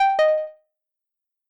フリー効果音：正解
フリー効果音｜ジャンル：システム、ピンポンピンポン！大正解な曲！